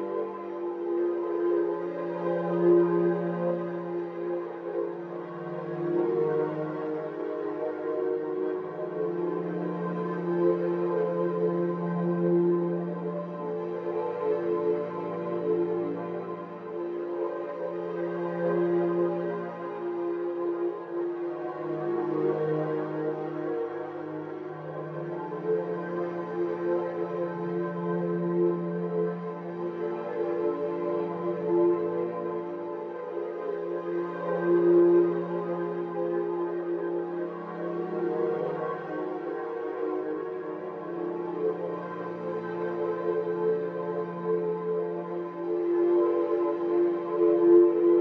simple shitty chord loop for the main menu